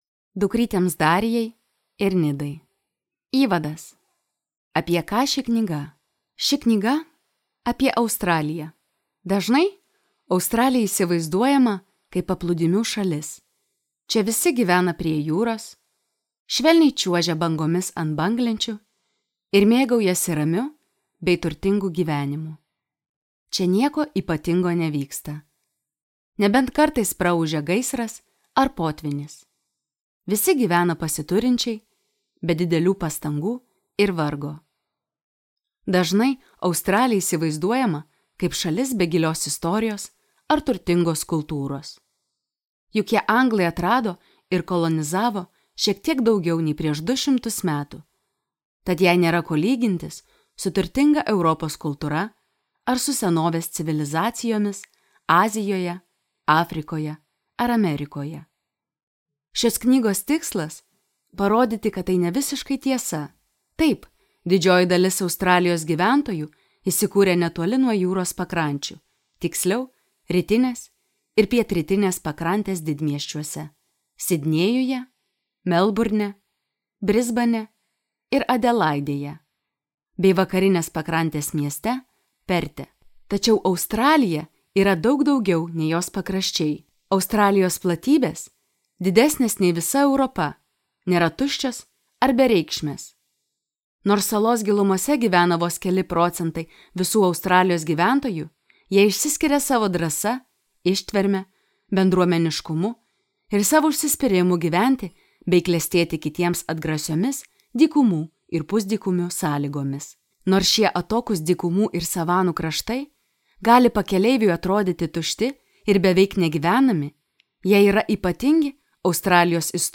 Australijos širdis | Audioknygos | baltos lankos